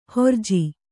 ♪ horji